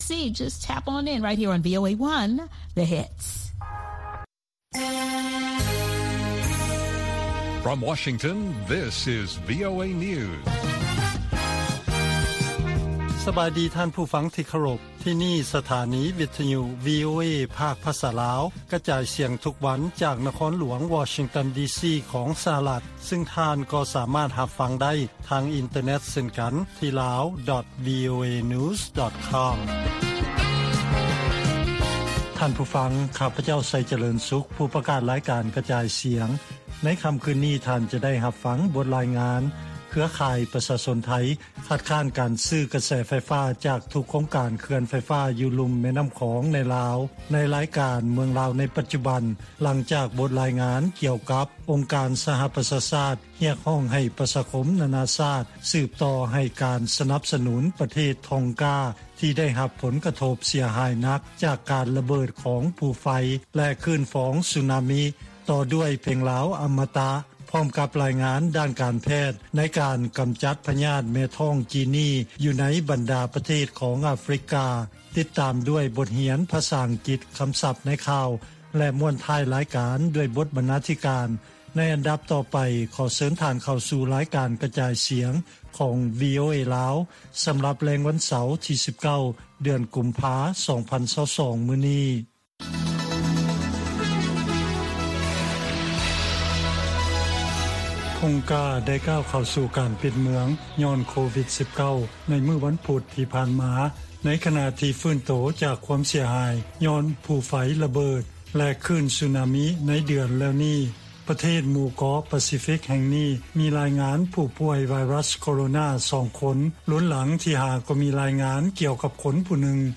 ລາຍການກະຈາຍສຽງຂອງວີໂອເອ ລາວ: ເຄືອຂ່າຍປະຊາຊົນໄທ ຄັດຄ້ານການຊື້ໄຟຟ້າ ຈາກທຸກໂຄງການເຂື່ອນໄຟຟ້າໃນລາວ